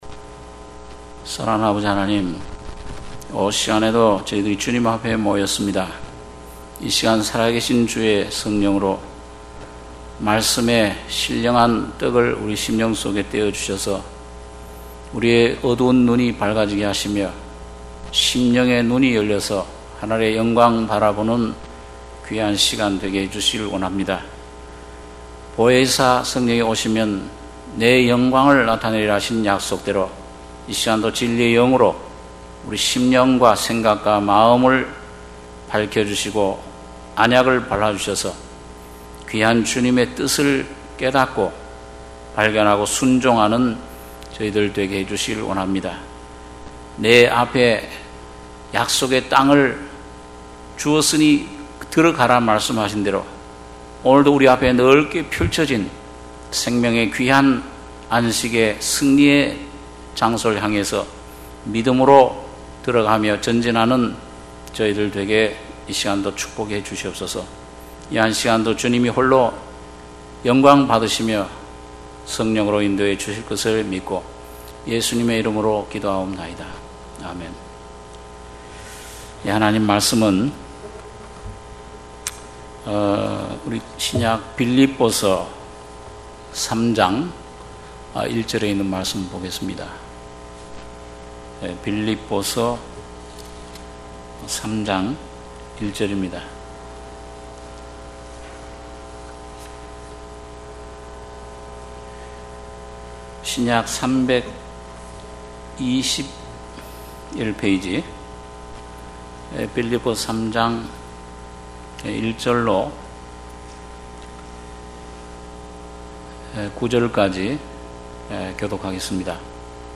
주일예배 - 빌립보서 3장 1-9절(주일오후)